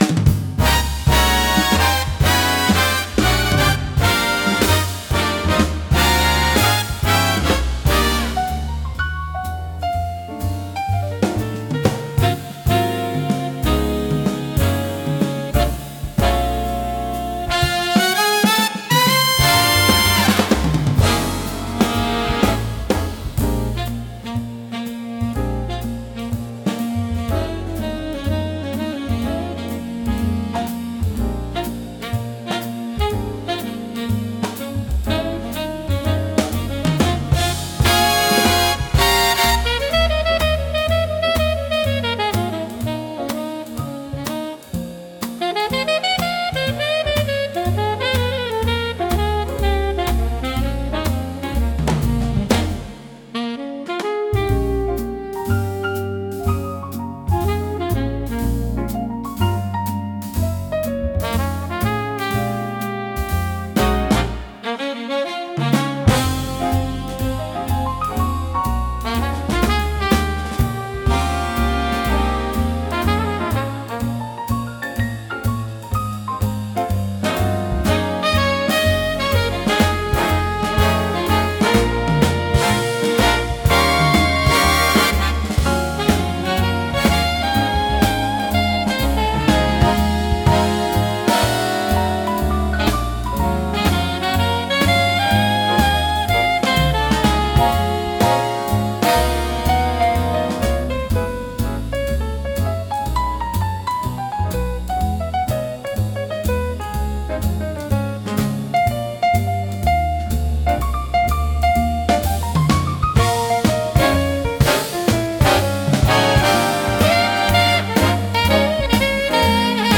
聴く人を楽しい気分にさせる迫力とノリの良さが魅力のジャンルです。